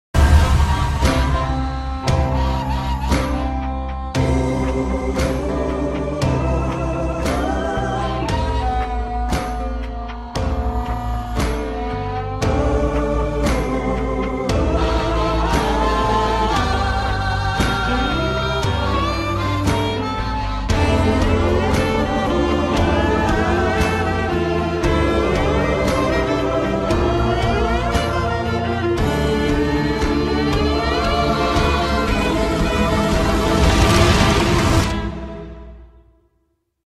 • Качество: 128, Stereo
саундтреки
инструментальные
госпел
Blues